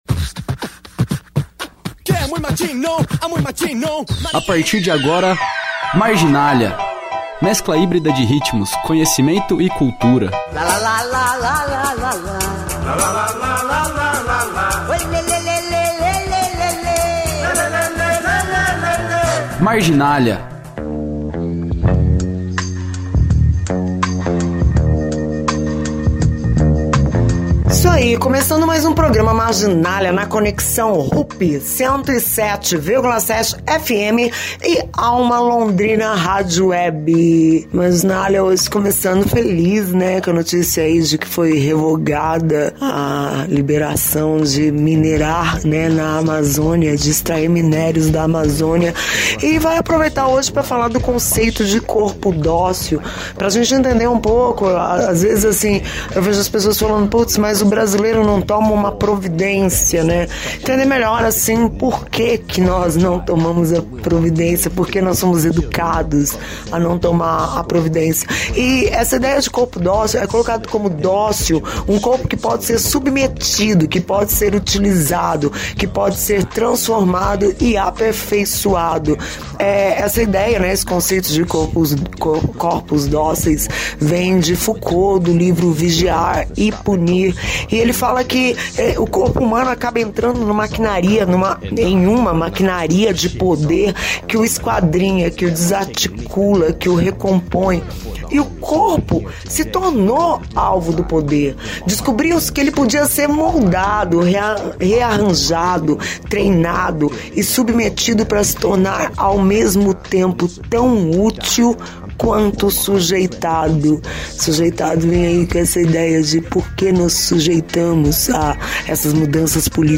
Essa semana o programa Marginália fala sobre o conceito de corpo dócil ao som do rock mexicano dos anos 70.